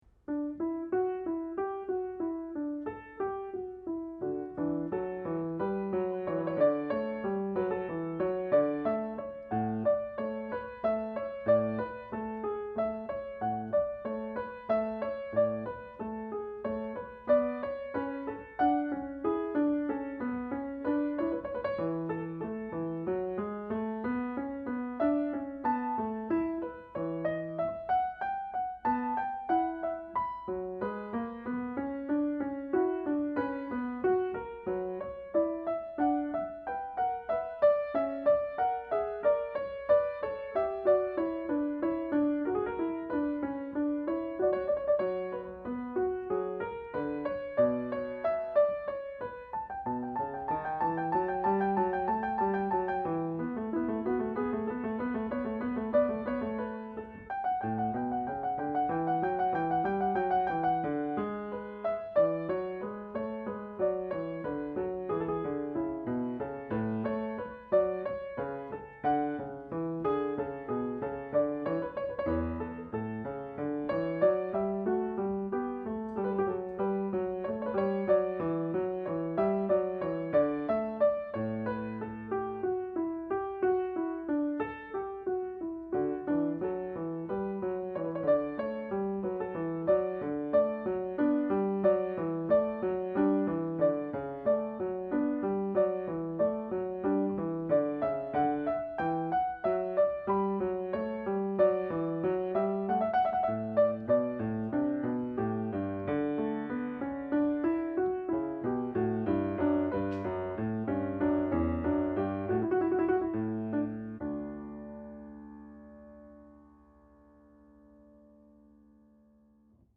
upgraded BM5